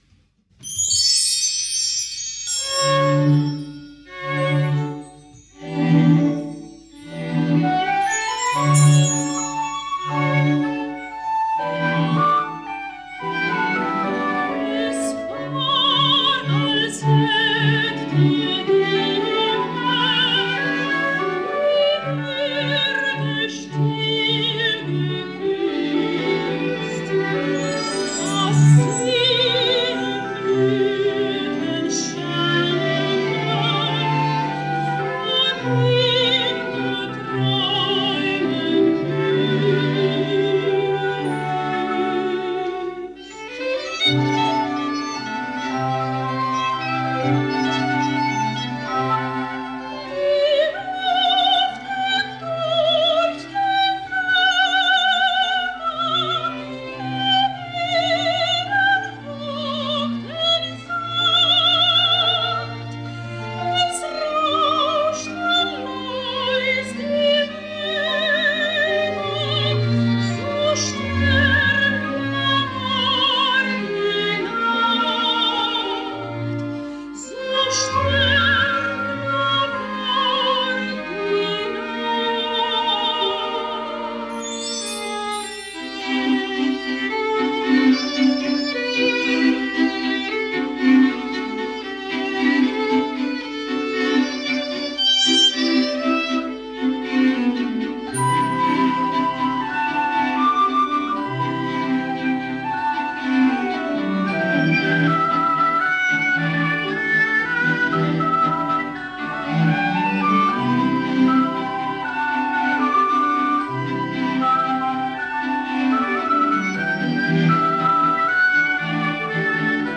Simply recorded with some cheap radio microphone)